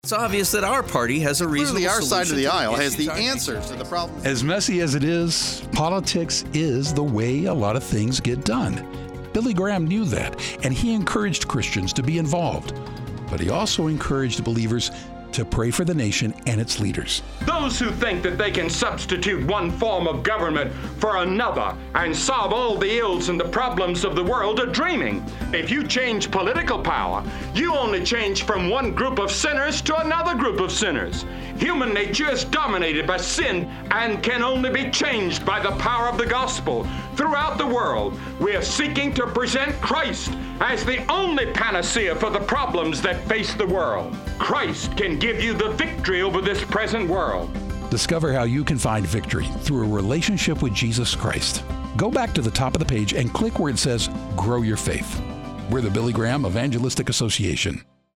Hear more in this short sermon.